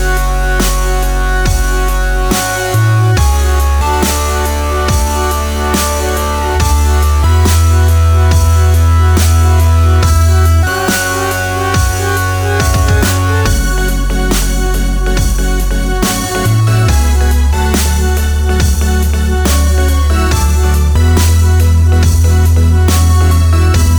no Backing Vocals Dance 3:27 Buy £1.50